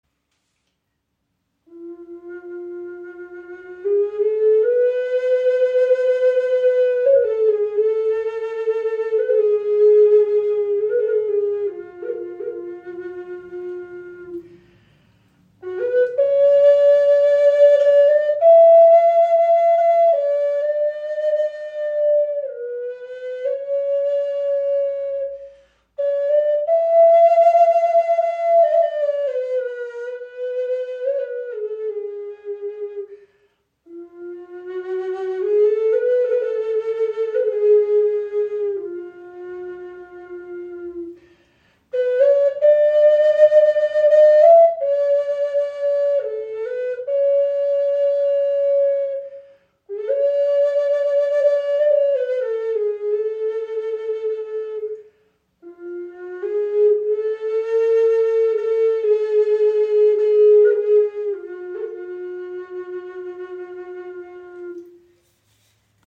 Okarina aus einem Aststück | G4 in 432 Hz | Aeolian Stimmung | ca. 27 cm
Handgefertigte 6 Loch Okarina aus Teakast – klarer, warmer Klang in Aeolischer Stimmung in G (432 Hz), jedes Stück ein Unikat.
Die Okarina spricht besonders klar an und erklingt in der Aeolischen Stimmung in G4, fein abgestimmt auf 432 Hz – ein Ton, der Herz und Geist in harmonische Schwingung versetzt.
Trotz ihrer handlichen Grösse erzeugt sie einen angenehm tiefen, warmen Klang – fast ebenbürtig zur nordamerikanischen Gebetsflöte.